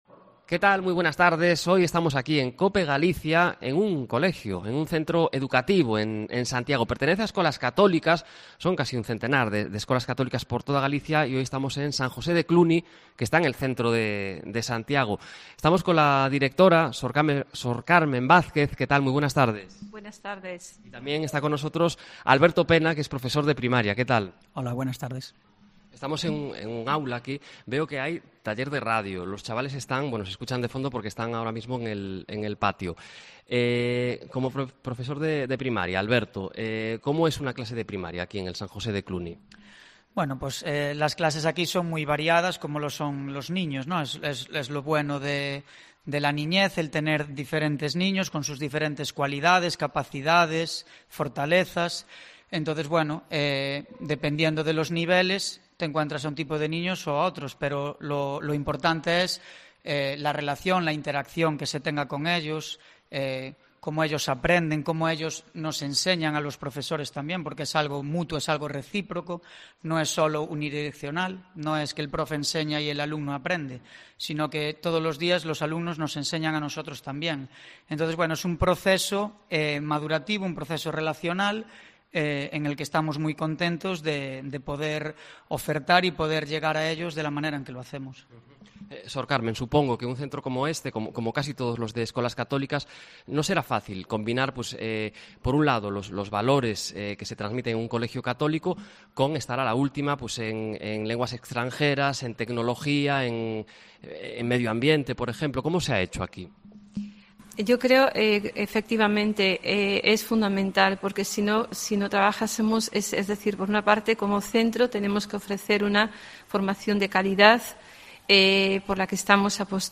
Hoy en Cope Galicia estamos en uno de esos centros, el San José de Cluny, en Santiago, prototipo de cómo es un colegio concertado en Galicia.